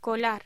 Locución: Colar
voz